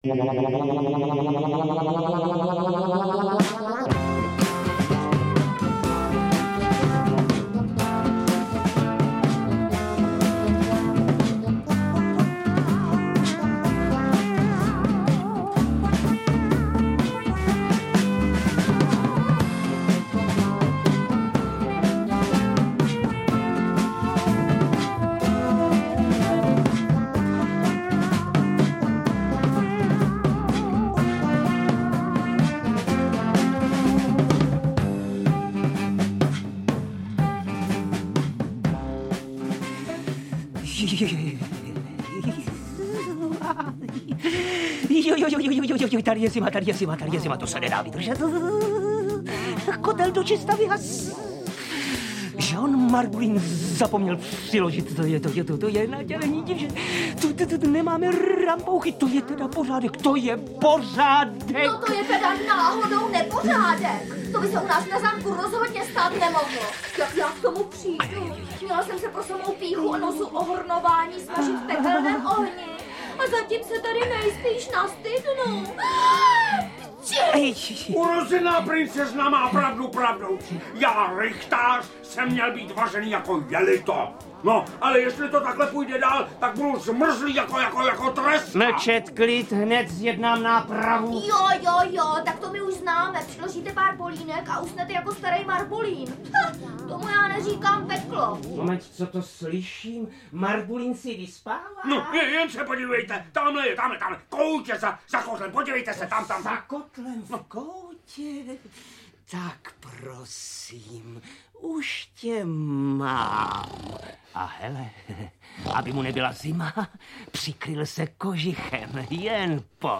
Nádherná pohádka veselejšího rázu, báječně namluveno.